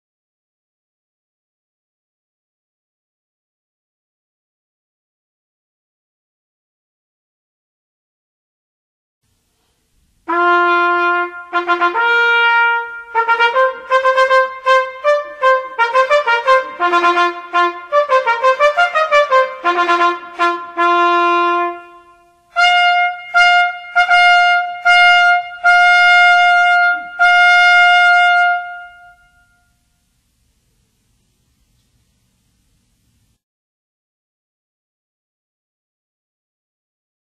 صوت أثناء مرور الملك